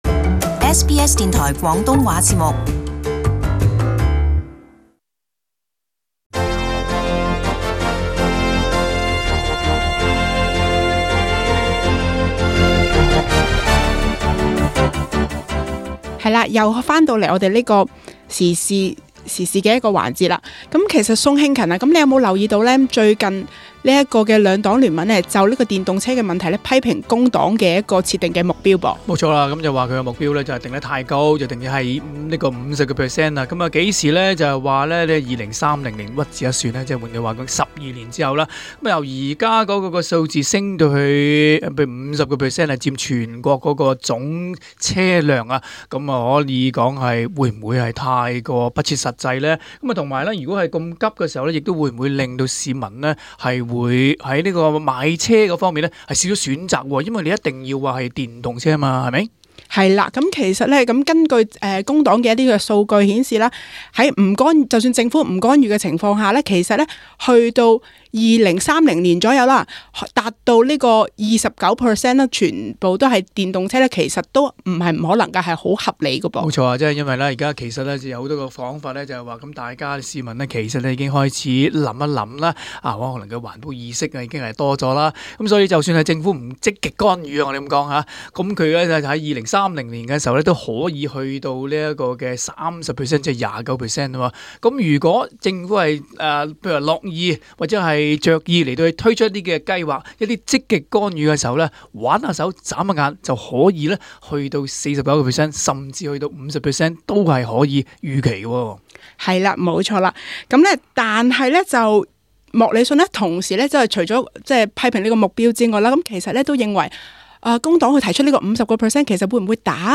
【時事報導】執政黨攻擊工黨電動車政策